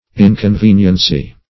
Inconveniency \In`con*ven"ien*cy\, n.